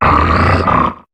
Cri de Grahyèna dans Pokémon HOME.